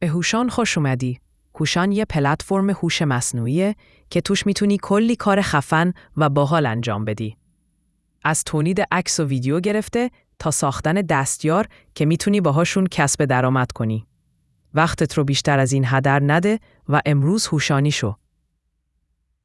openai-fm-alloy-sympathetic.wav